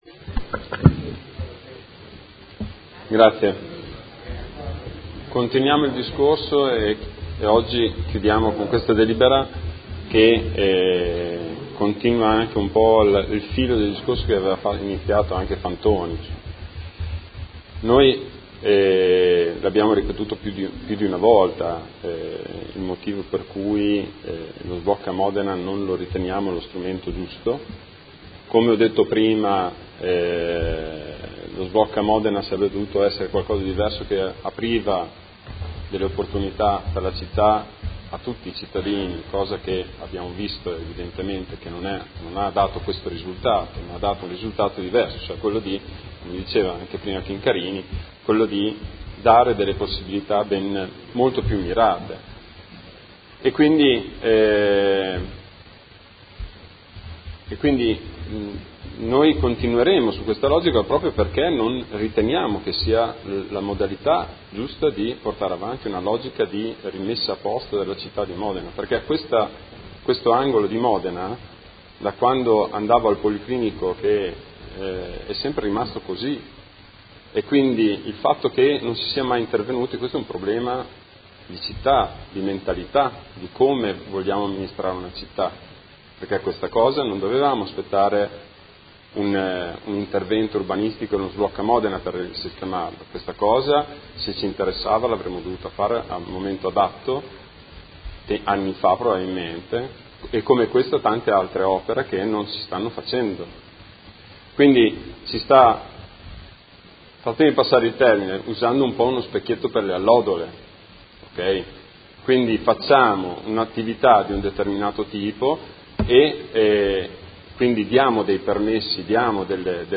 Seduta del 13/07/2017 Dibattito.
Audio Consiglio Comunale